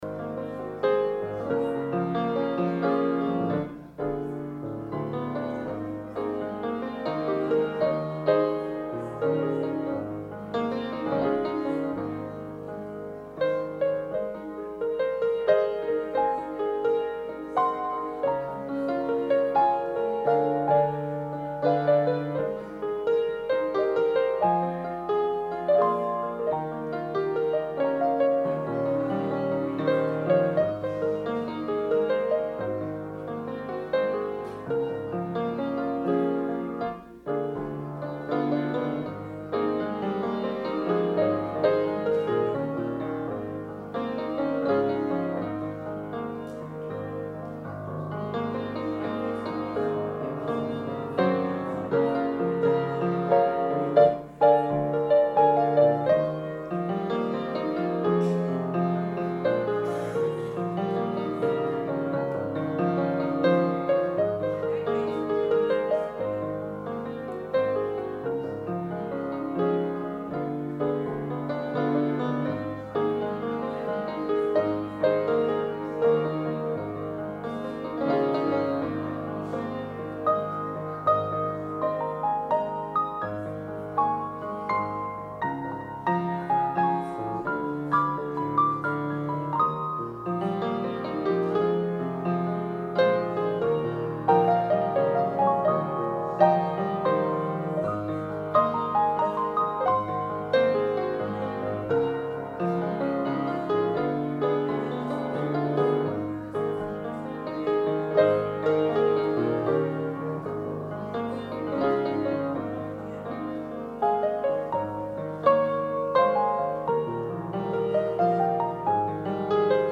Sermon – August 25, 2019